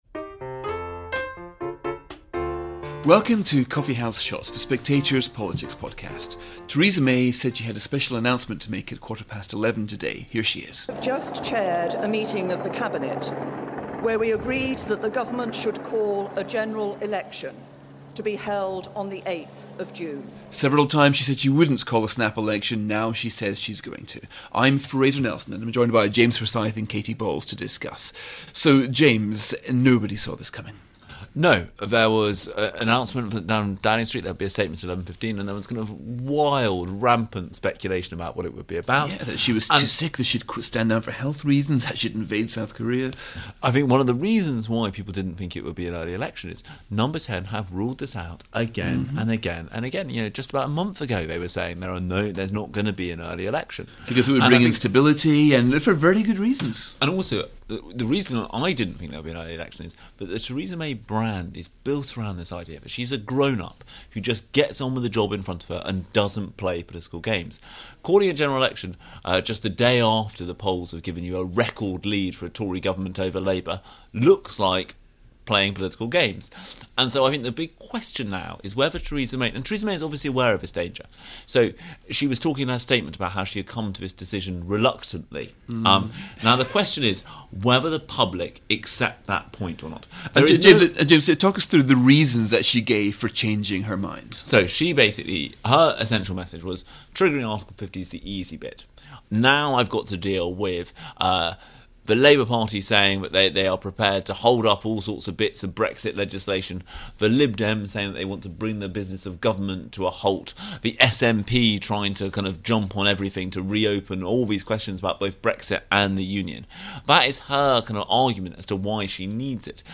Presented by Fraser Nelson.